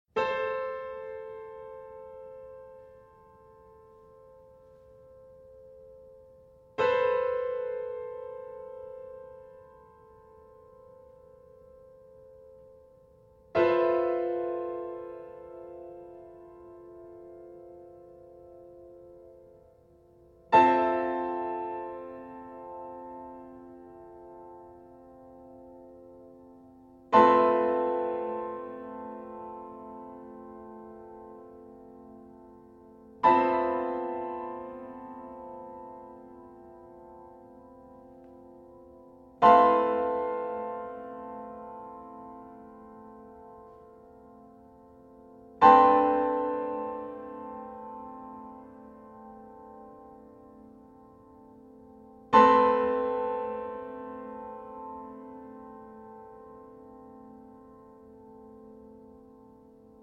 bassoon
Piano